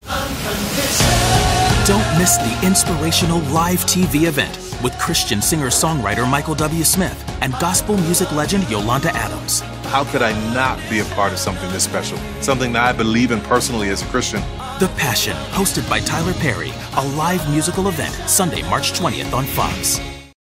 network/cable : men